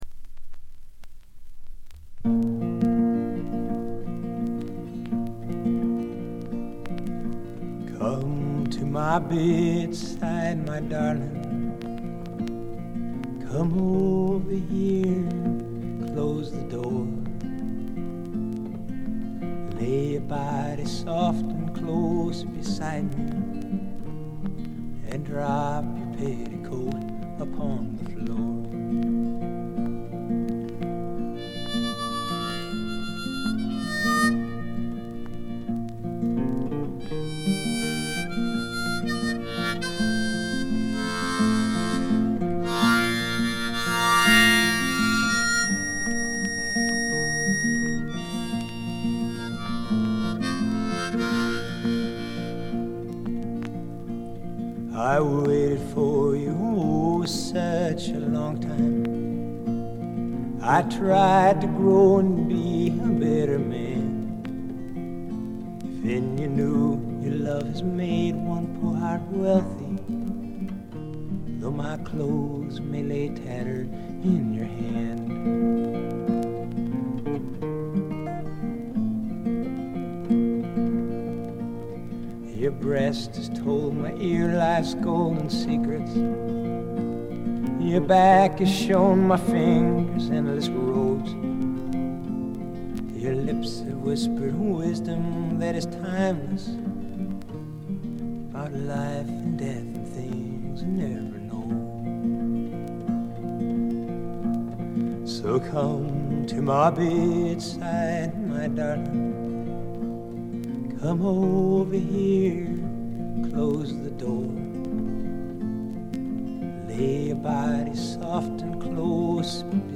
バックグラウンドノイズ、チリプチ多め大きめ。プツ音、周回気味のノイズも。
2曲でセカンド・ギターが付くほかはすべて自身の弾き語りというとてもシンプルなものです。
米国フォーク／シンガーソングライターの基本。
試聴曲は現品からの取り込み音源です。
lead vocals, guitar, harmonica